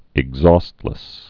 (ĭg-zôstlĭs)